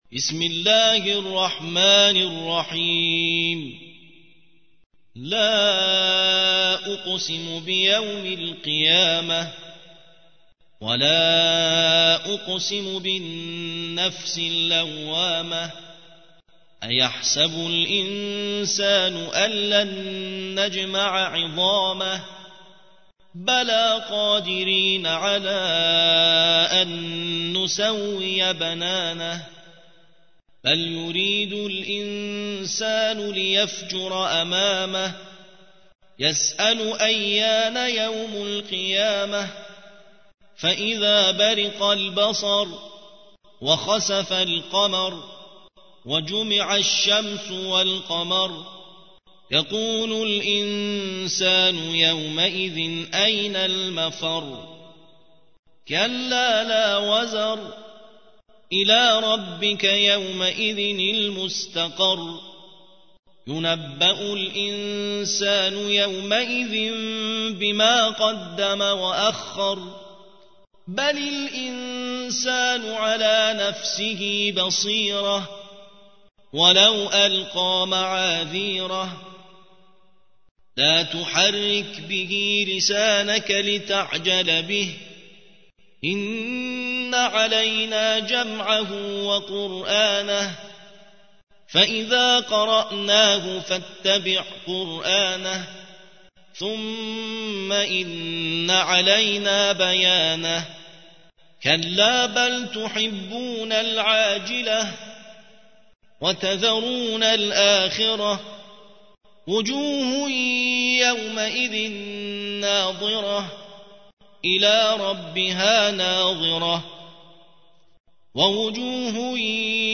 مجموعه منتخبی از قرائت های قرانی